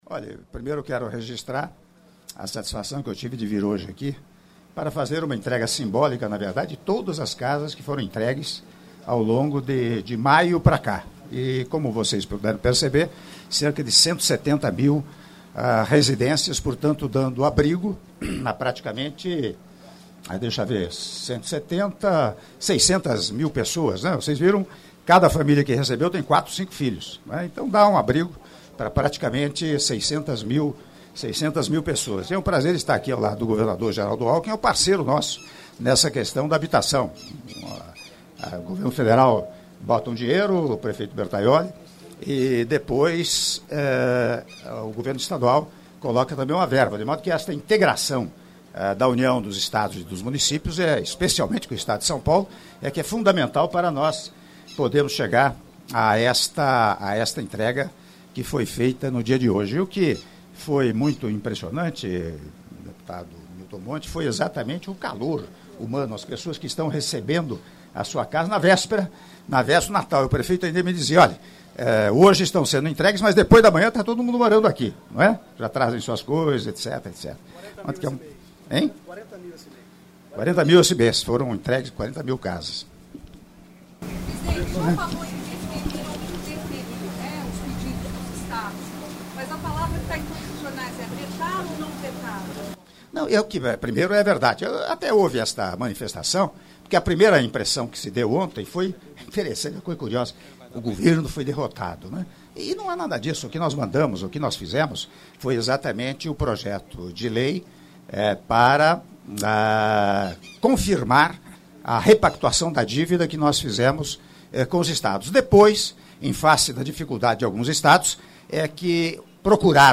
Áudio da entrevista do presidente da República, Michel Temer, após cerimônia de entrega de 420 unidades habitacionais do Residencial Mogi das Cruzes - Itapevy, Ype, Manacá, Tietê e Maitaca, do Programa Minha Casa Minha Vida - Mogi das Cruzes - (07min30s)